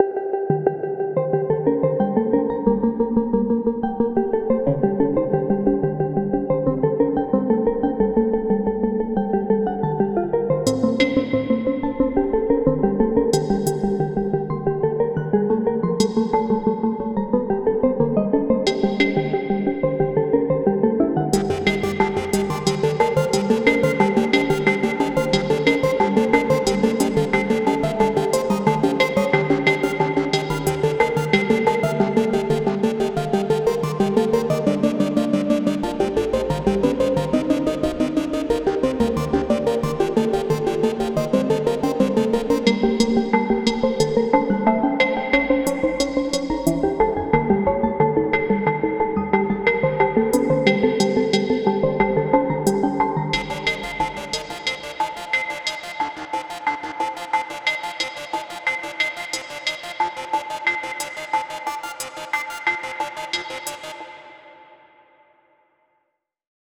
Pieza de electrónica experimental
Música electrónica
percusión
melodía
repetitivo
rítmico
sintetizador